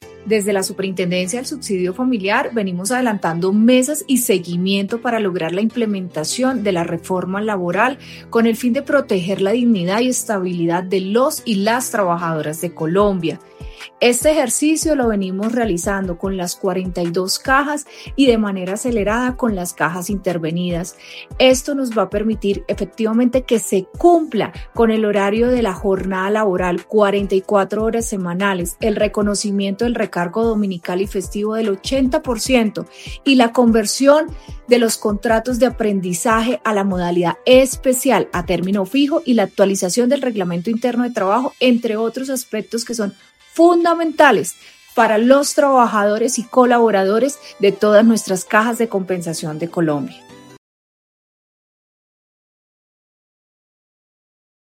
Sandra Viviana Cadena Martínez, superintendente del Subsidio Familiar.